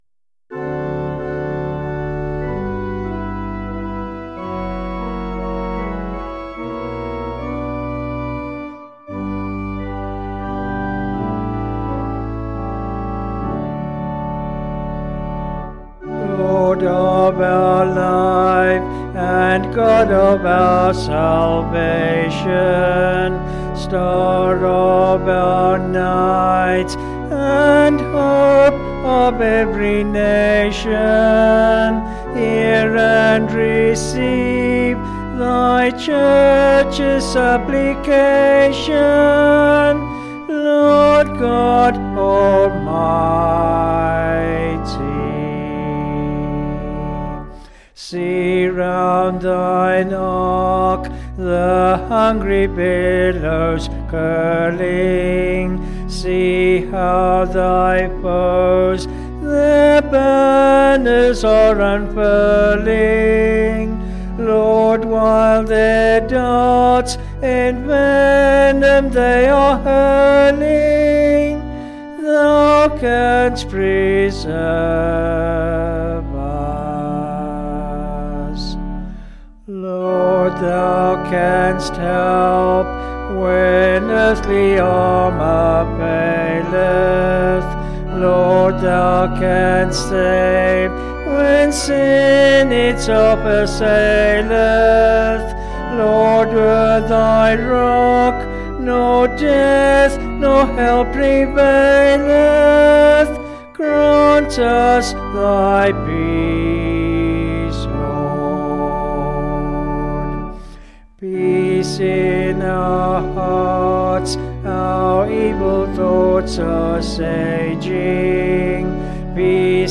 Vocals and Organ   264.3kb Sung Lyrics